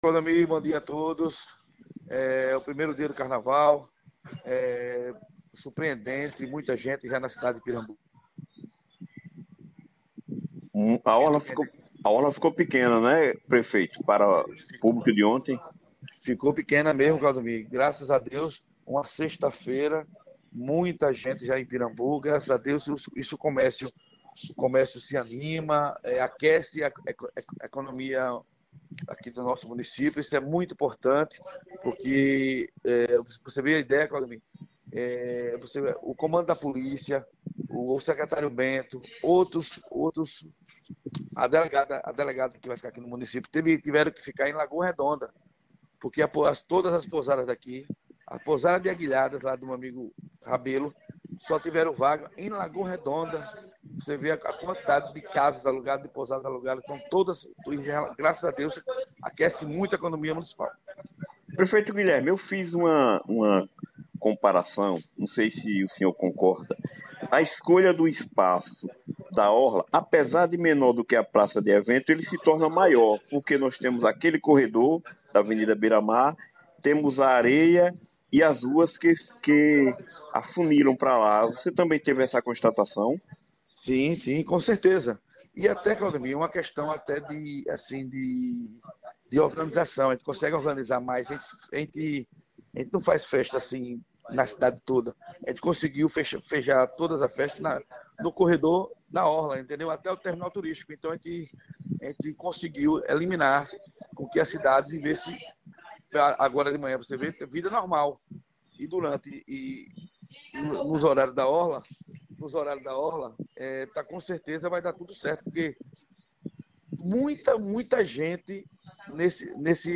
A rádio (e jornal) Tribuna da Praia está levando até você, de hora em hora, flash’s AO VIVO (e recuperados) do Carnaval de Pirambu, o maior e melhor de Sergipe. Entrevistado na manhã deste sábado, 18, o prefeito Guilherme Melo, fez um balanço do primeiro dia da festa momesca e disponibilizamos o áudio abaixo:
Áudio: Entrevista com GUILHERME MELO, prefeito de Pirambu, faz balanço do primeiro dia de Carnavsl